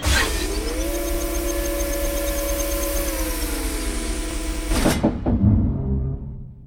dock2.ogg